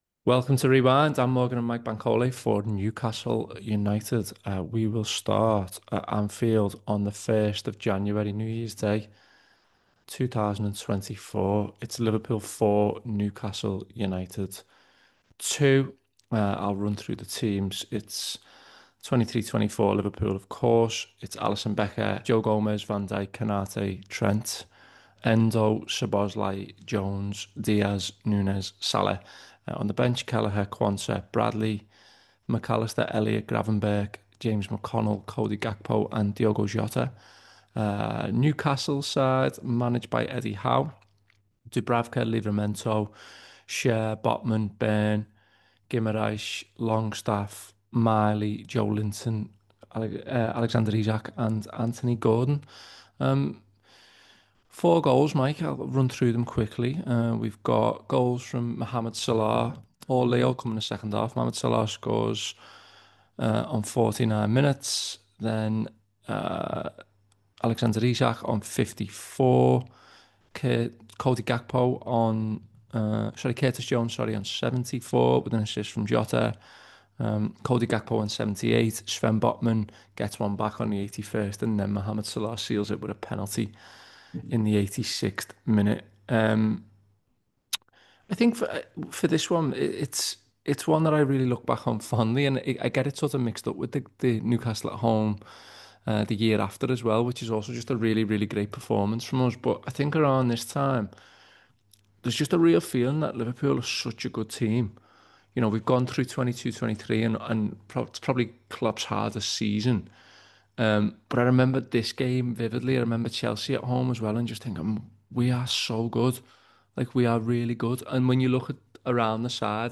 Below is a clip from the show – subscribe to The Anfield Wrap for more Liverpool chat…